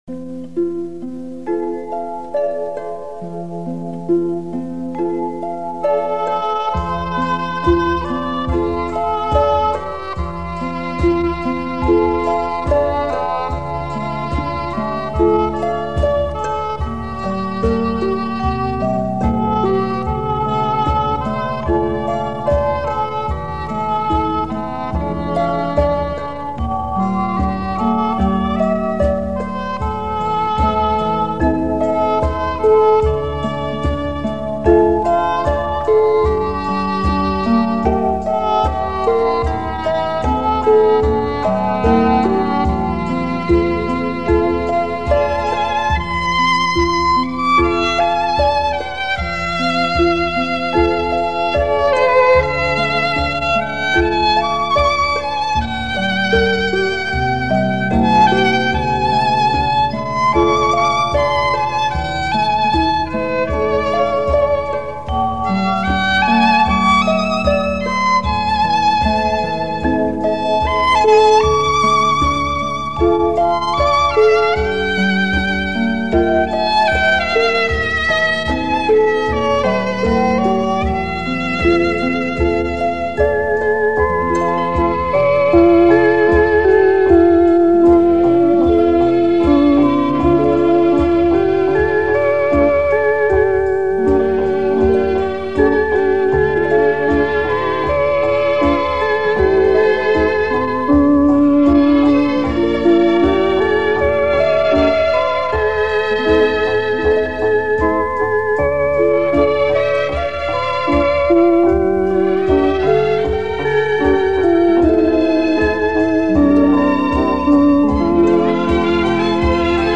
类型: 盒式磁带